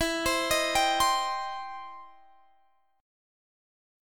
Listen to EmM7#5 strummed